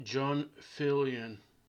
John Filion (/ˈfɪliən/
FIL-ee-ən; born 1950) is a former Toronto city councillor represented Ward 18 Willowdale.[1] Although he had planned to retire in 2018, after the legislation from Premier Doug Ford which expanded ward boundaries, he decided to run for re-election because he believes it makes it "virtually impossible for a community activist, known within one area, to get elected".[2] He announced on June 10, 2022, that he would not be running in the October 2022 Toronto municipal election, retiring after 40 years in municipal politics.
Johnfilionname.ogg.mp3